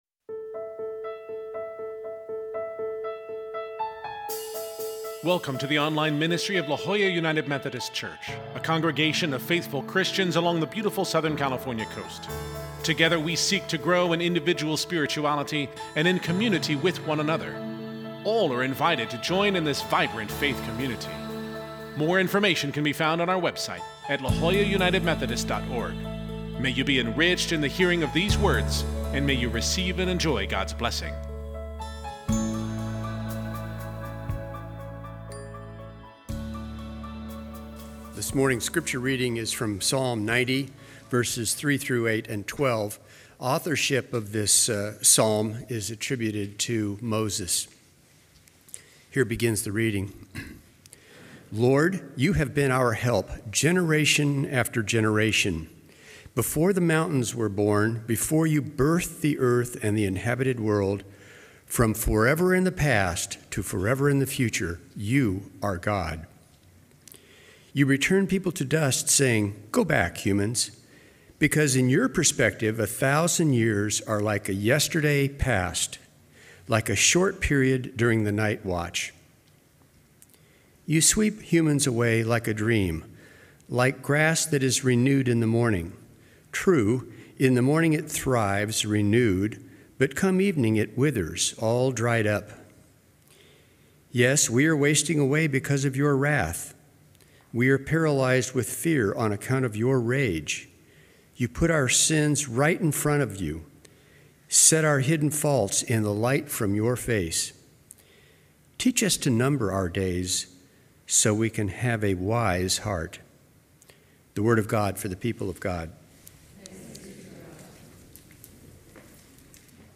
Scripture: Psalm 90:1-8, 12 (CEB) worship bulletin Sermon Note Share this: Print (Opens in new window) Print Share on X (Opens in new window) X Share on Facebook (Opens in new window) Facebook